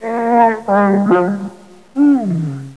Grunts2 (60 kb)